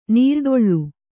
♪ nīrḍoḷḷu